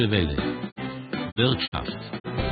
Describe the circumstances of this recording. JUMA-RX1 DRM receiver